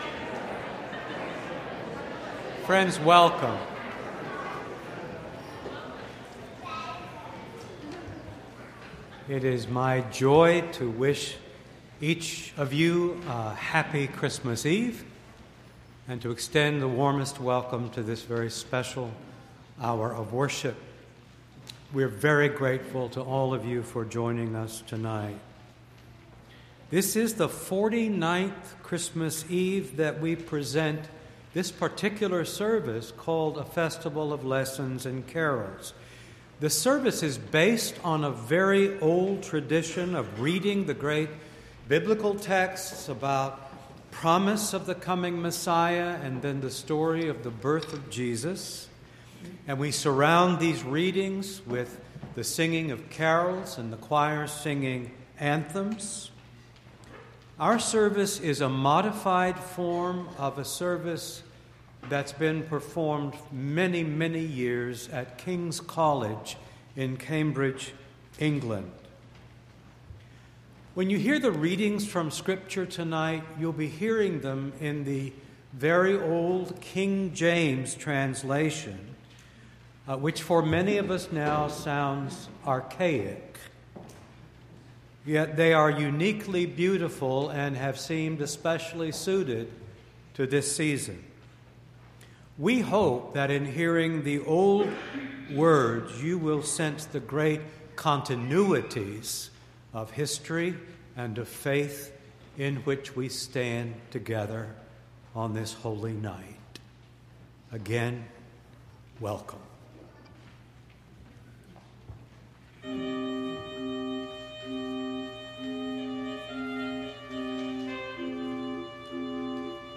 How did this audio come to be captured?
Entire December 24th Service